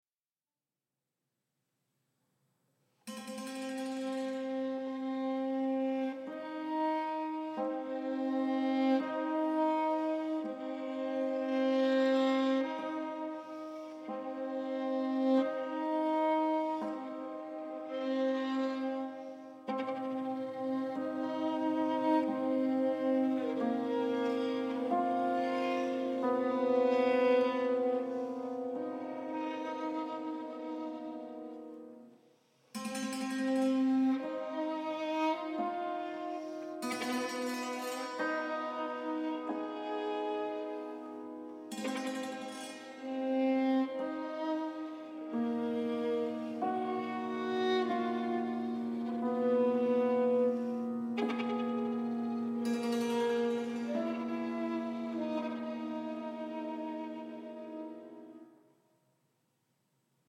for violin and cimbalom